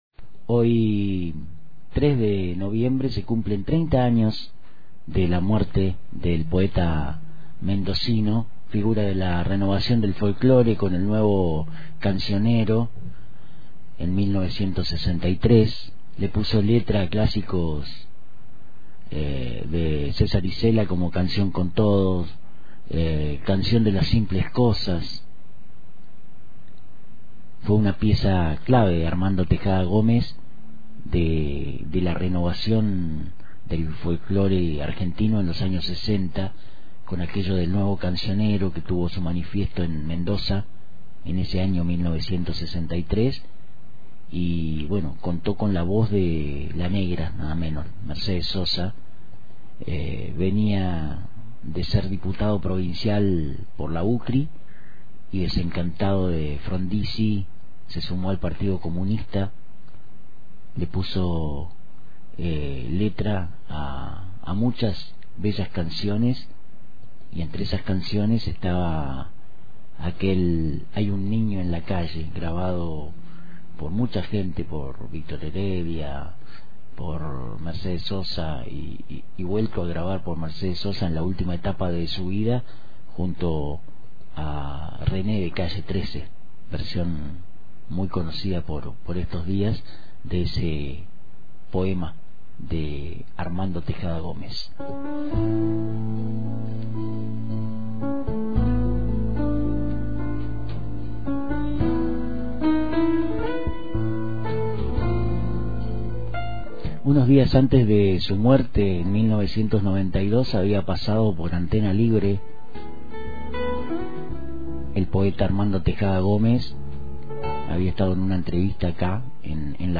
Homenaje en Isla de Radio: Armando Tejada Gómez, a 30 años de su partida